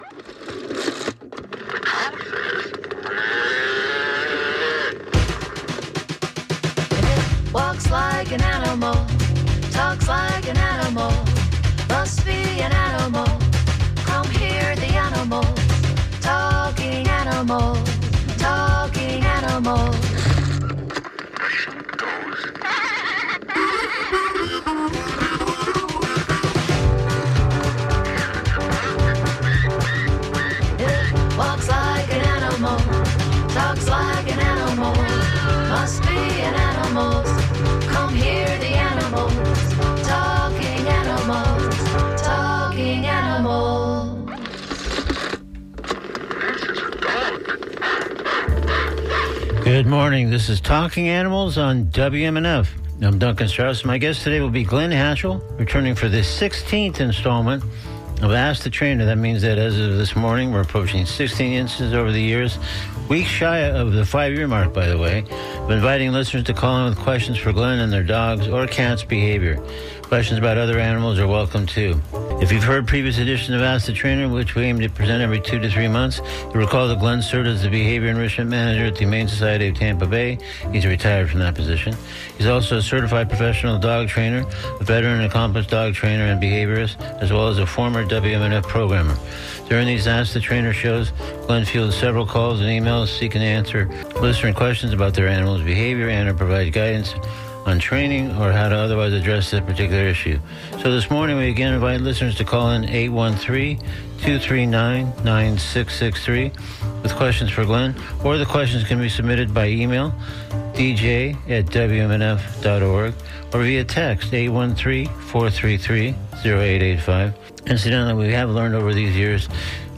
In each session of ATT, listeners are invited to call or email (or text) questions about their dogs or cats.
” instrumentals